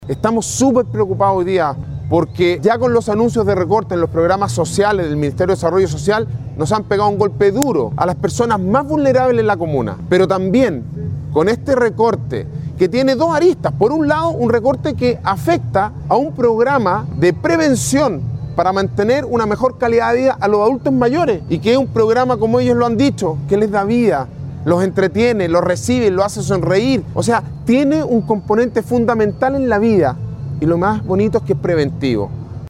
Alcalde-Sebastian-Alvarez-preocupado-por-recortes-en-Presupuesto-social-.mp3